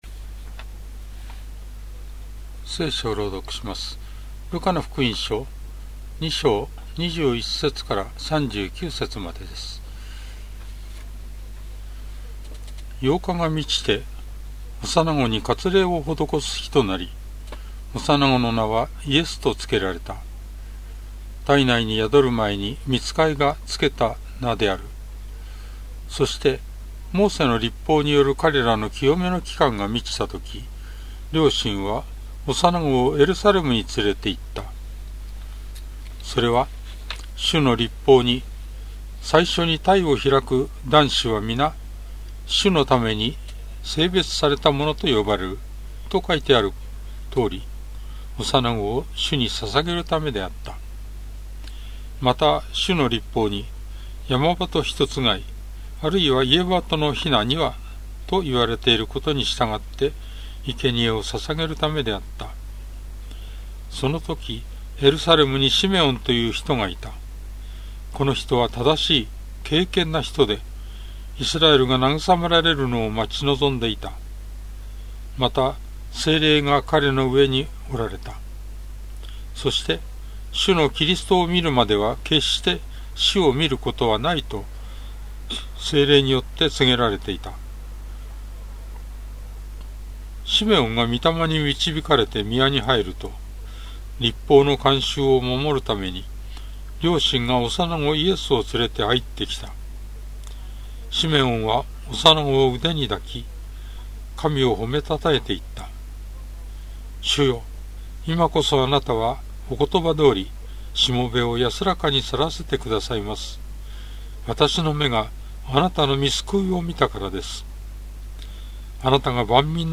BibleReading_L2.21-39.mp3